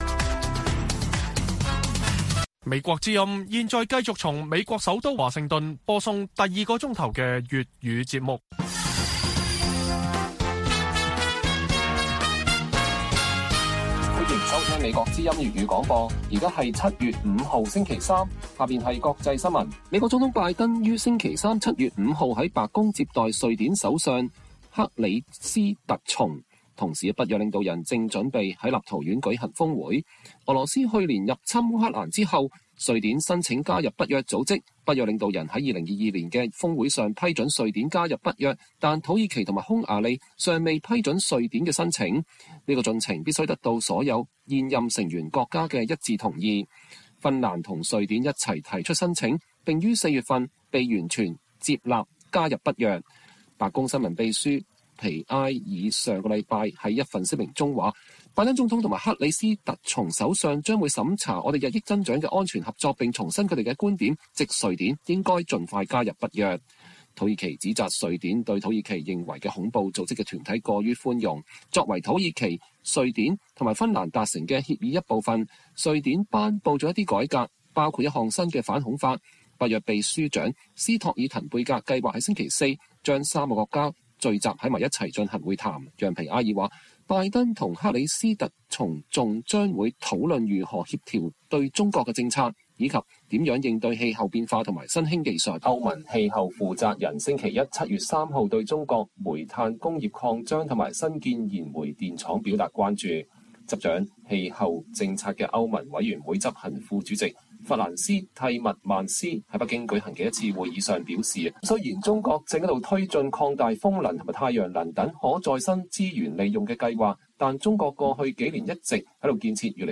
粵語新聞 晚上10-11點: 美國財長耶倫訪華預計難有重大突破